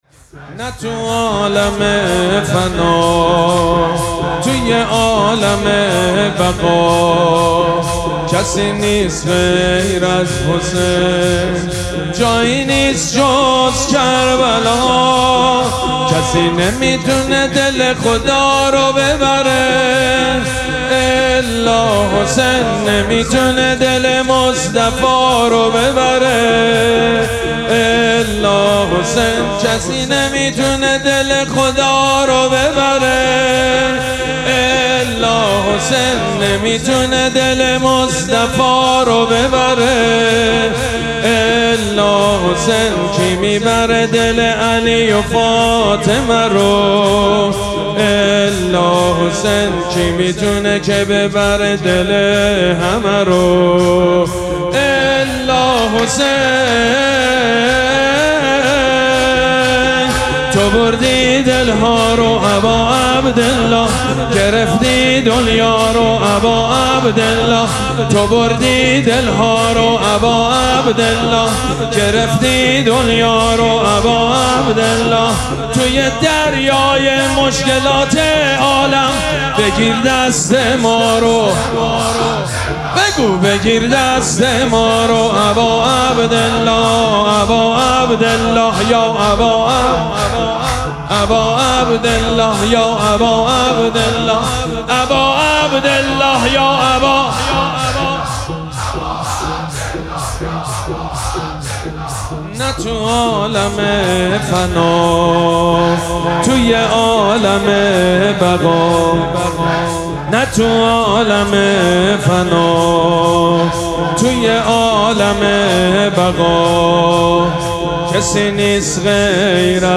مراسم عزاداری شام شهادت حضرت زینب سلام‌الله‌علیها
شور
مداح
حاج سید مجید بنی فاطمه